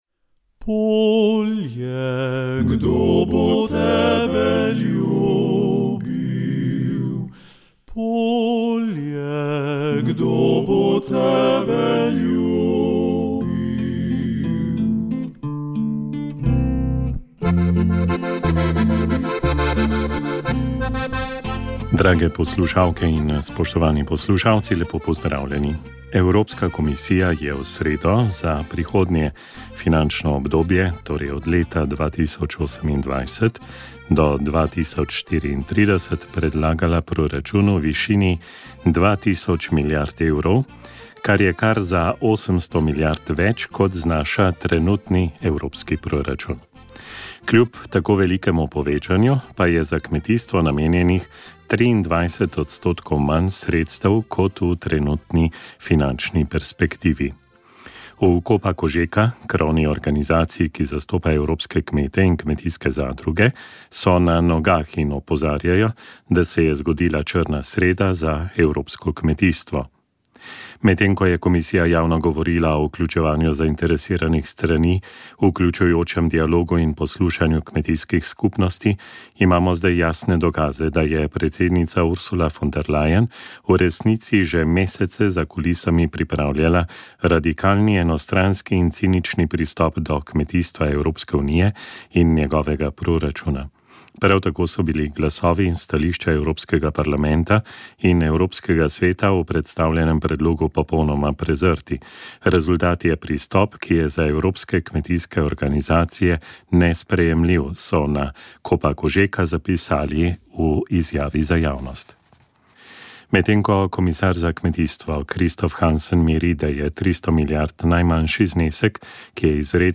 Uvodoma smo prisluhnili duhovnemu nagovoru koprskega škofa Petra Štumpfa. Sledili sta molitvi prvih nedeljskih večernic in rožnega venca, s katerim smo prosili za mir v svetu. Drug del večera je bil namenjen Radijski katehezi: Blagor tistemu, ki ni izgubil upanja - Duhovno življenje v bolezni in starosti.